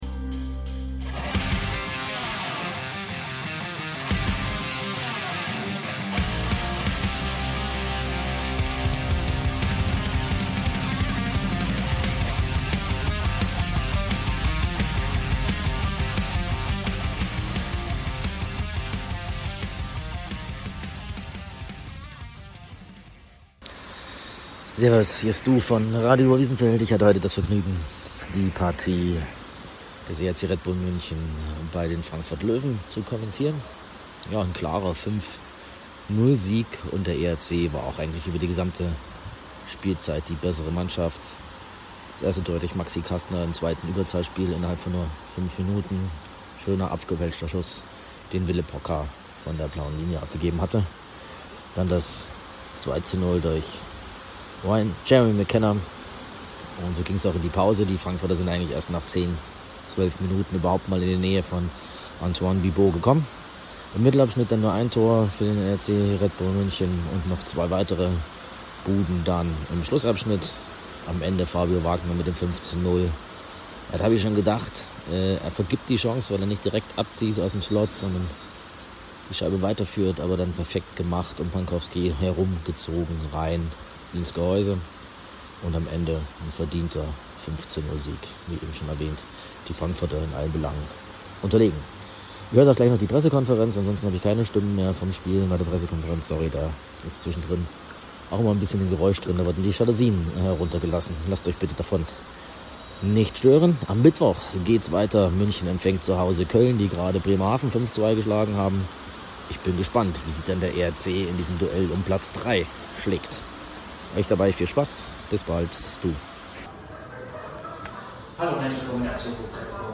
Spielzusammenfassung und Stimmen